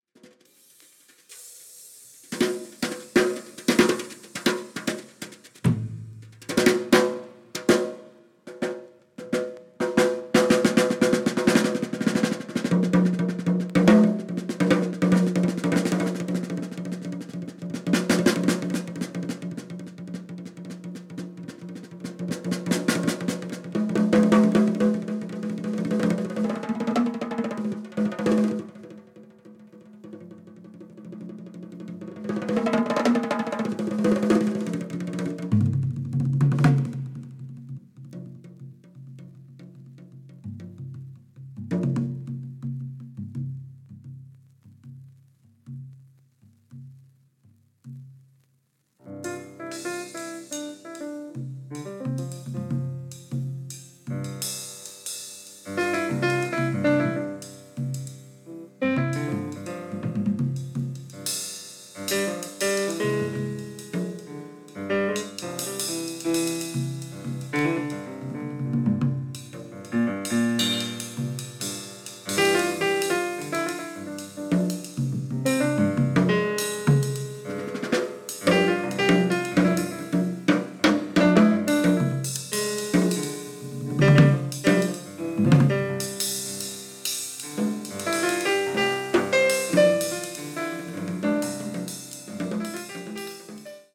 Percussion
Piano